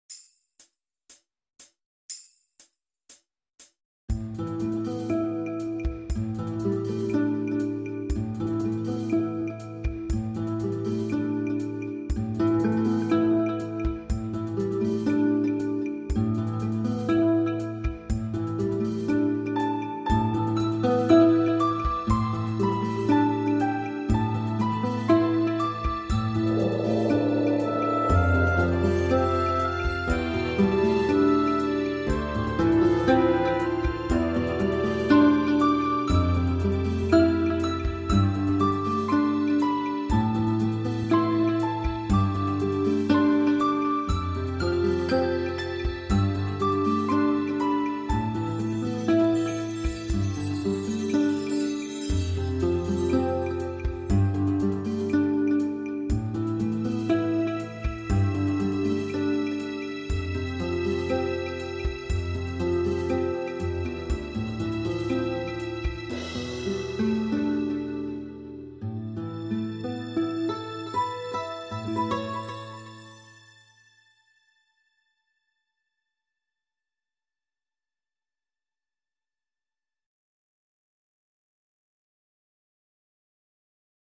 Pr. Accomp